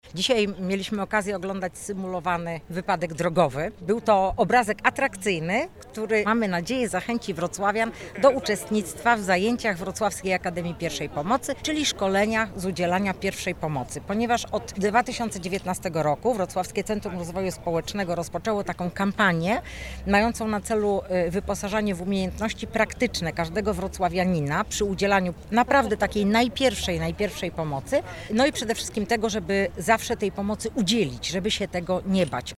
W centrum Wrocławia (pl. Nowy Targ) zorganizowano plenerowe szkolenie z zakresu udzielania pierwszej pomocy medycznej.